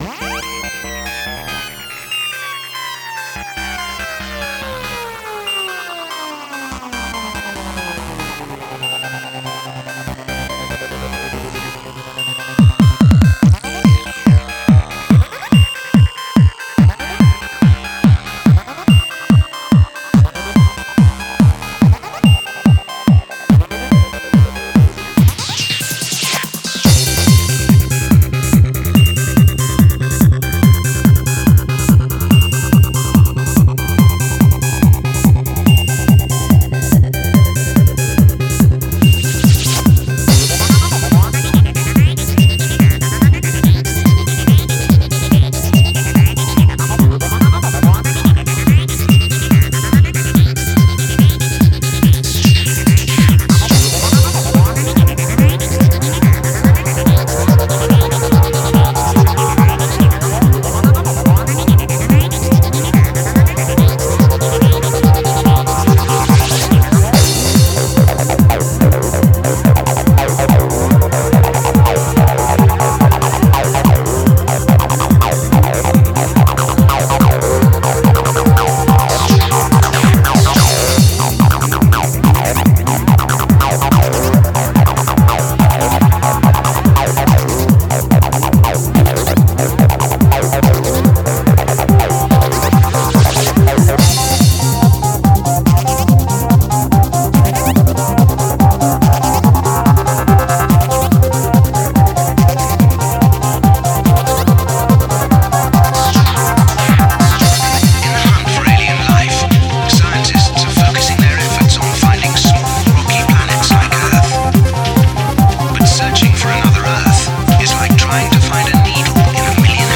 Файл в обменнике2 Myзыкa->Psy-trance, Full-on
Style: Goa Trance
Quality: 320 kbps / 44,100 Hz / Full Stereo